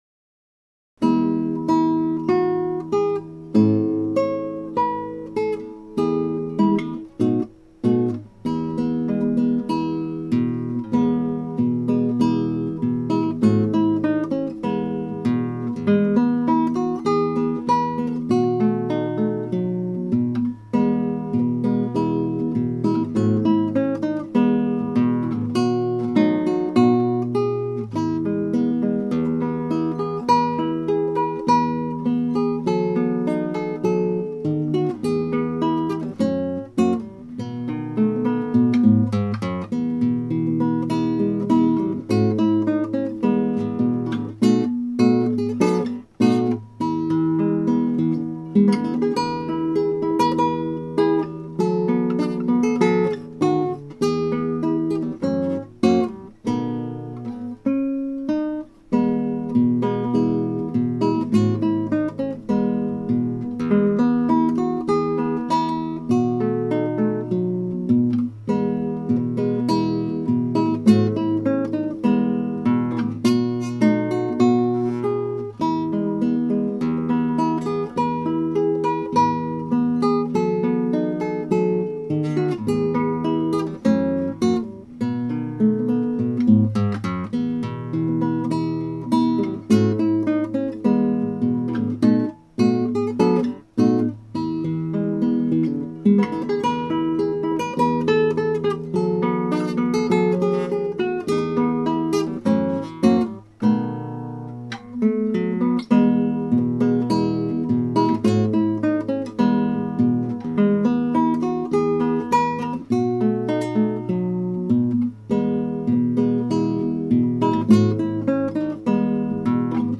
ギター演奏ライブラリ
(アマチュアのクラシックギター演奏です [Guitar amatuer play] )
2番、3番の歌のメロディーラインと間奏の符点音符を複符点にしてより原曲に合わせて(ミスタッチでの音違い、音落ちはご容赦を)弾き直しました。
演奏は練習不足の仕上がりです。
演奏は少々、ミスがあり、またエンディングもリタルランド、フェルマータの後、アテンポなのですが不十分で遅いままで終わってますが良しとしました。